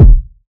Kick (T-Minus).wav